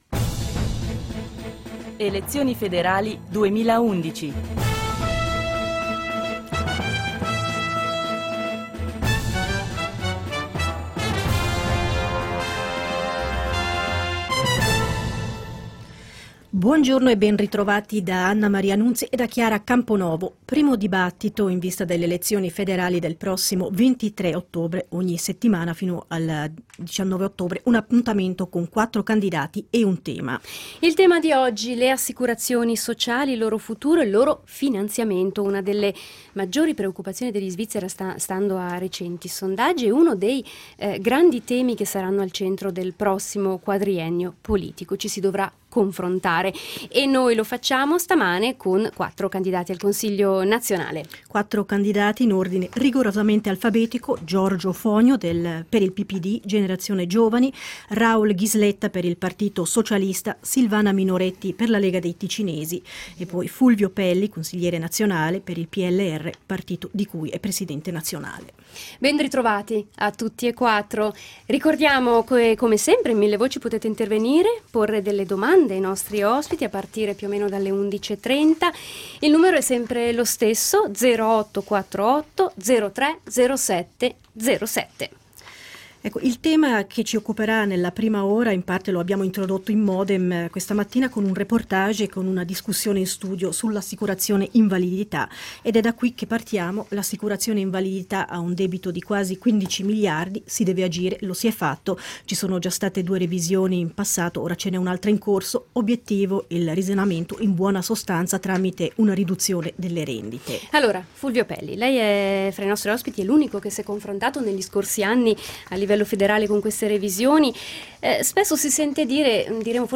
Il 7 settembre a Millevoci, nel primo dibattito radiofonico in vista delle elezioni federali, a partire dalle 10.45, quattro candidati illustreranno e discuteranno le ricette dei loro partiti per riformare il sistema pensionistico e le altre assicurazioni sociali, alle prese con buchi miliardari, come l'AI.